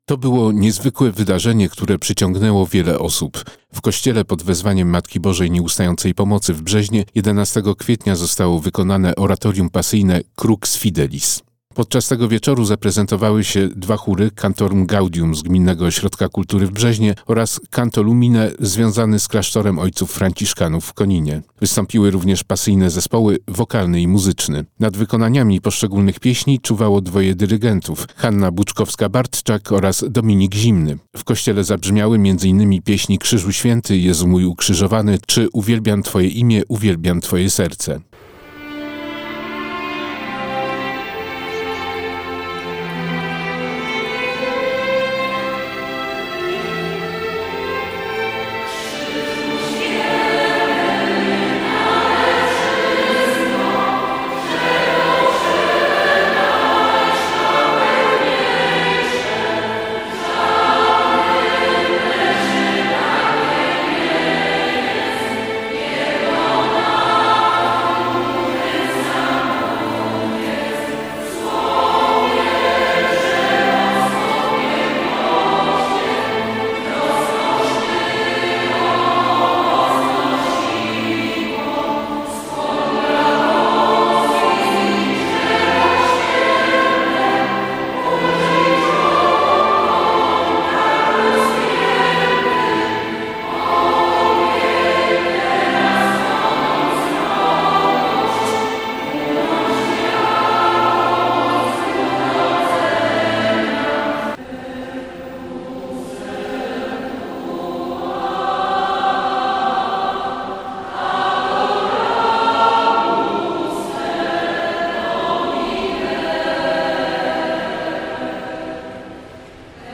W kościele pod wezwaniem Matki Bożej Nieustającej Pomocy w Brzeźnie, jedenastego kwietnia zostało wykonane oratorium pasyjne „Crux Fidelis”.
Podczas tego wieczoru zaprezentowały się dwa chóry: Cantorum Gaudium z Gminnego Ośrodka Kultury w Brzeźnie oraz Canto Lumine, związany z klasztorem franciszkanów w Koninie. Wystąpiły również pasyjne zespoły wokalny i muzyczny.
W każdym razie zabrzmiało to pięknie, kiedy trzeba, monumentalnie, kiedy trzeba, kameralnie.
I potrzebnym, o czym świadczyła liczna obecność wiernych w kościele oraz długie owacje na stojąco.